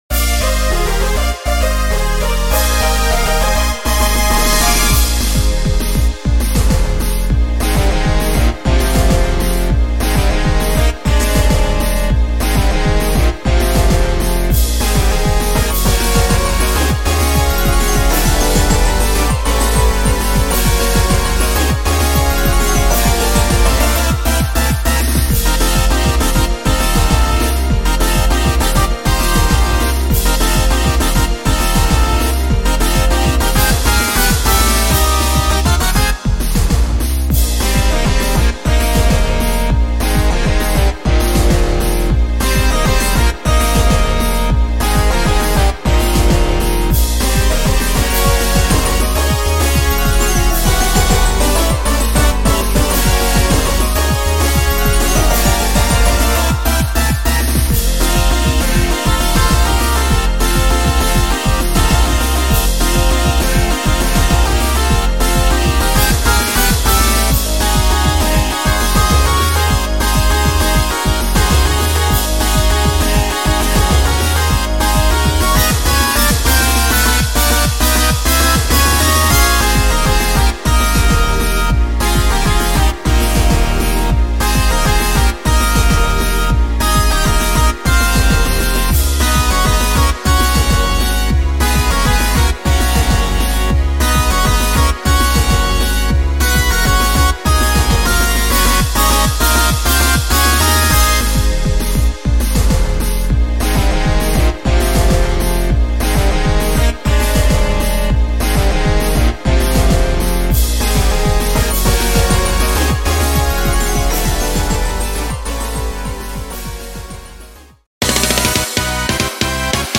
I made a remix of the fourth battle course.